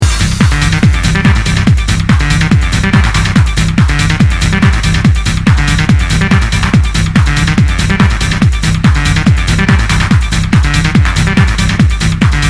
hardtrance tune id2 from 2000?